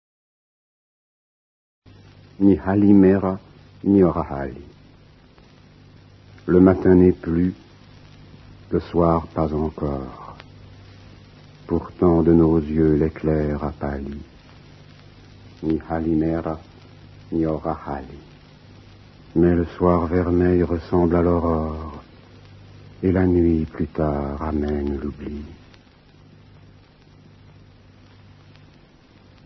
/wp-content/uploads/2011/01/nibonjournibonsoir.mp3 dit par Alain CLUNY Gérard DE NERVAL ( Petits châteaux de Bohème , 1853)